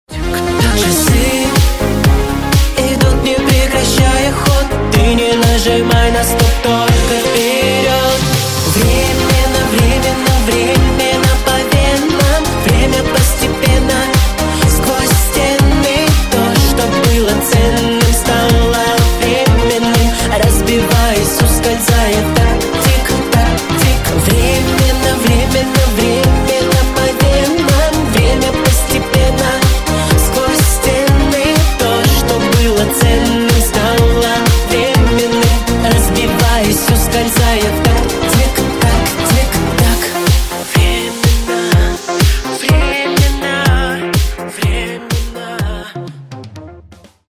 • Качество: 320, Stereo
поп
мужской и женский вокал